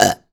pgs/Assets/Audio/Comedy_Cartoon/comedy_burp_01.wav
comedy_burp_01.wav